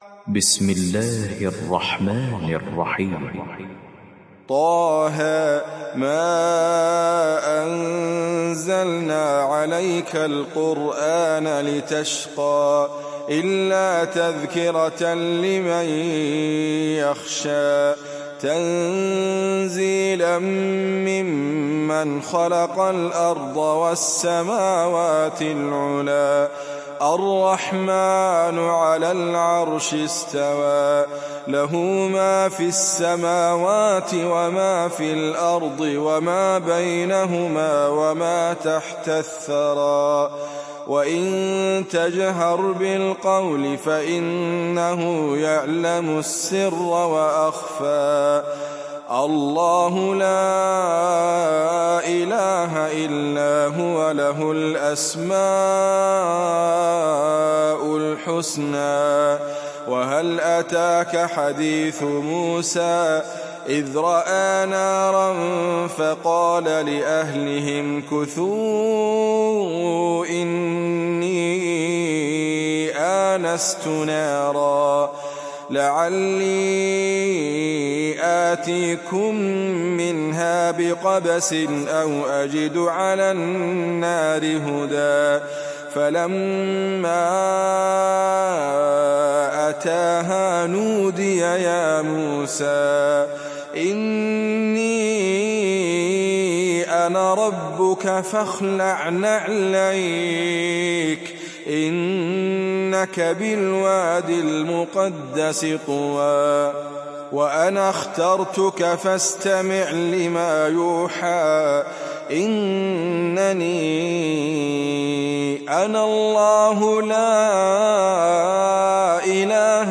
Taha Suresi İndir mp3 Idriss Abkar Riwayat Hafs an Asim, Kurani indirin ve mp3 tam doğrudan bağlantılar dinle